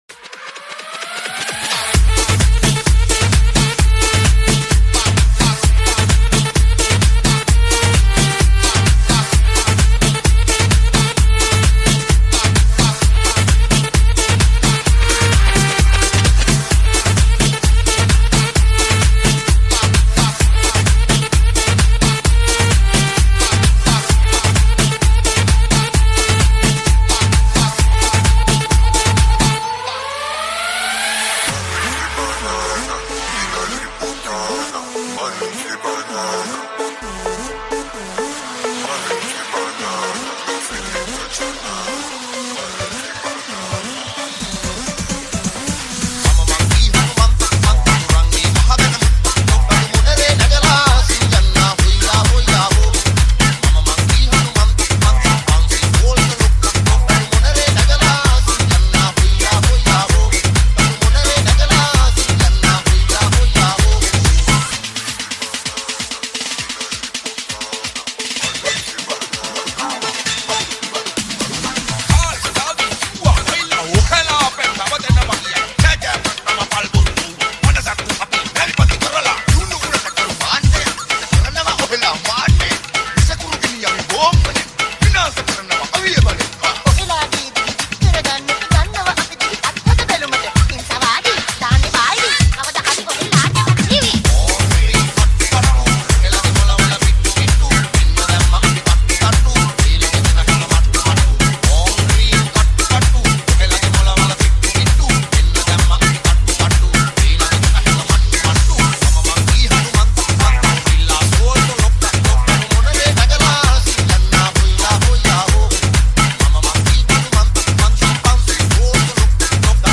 House Remix